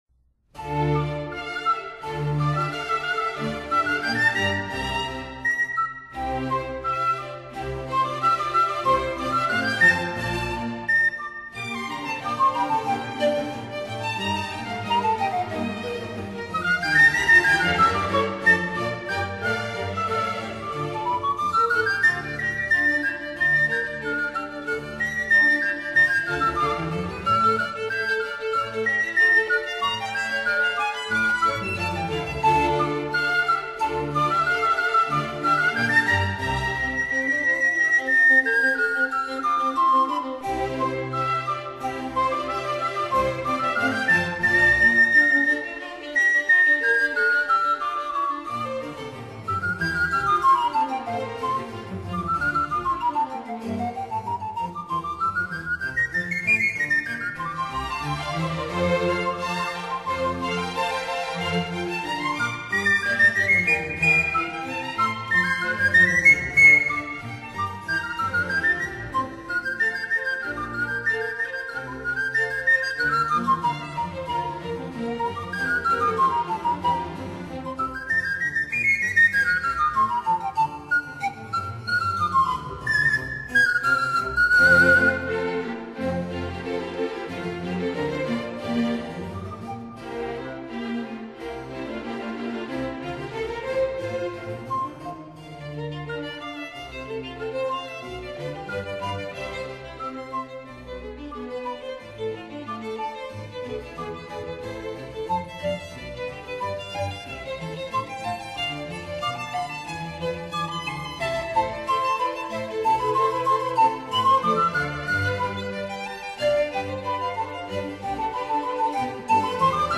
排笛(排蕭) Panpipes又稱潘神蕭，也稱為西林克斯，由許多粗細長短不等的音管連結在一起，外表看起來很像木筏。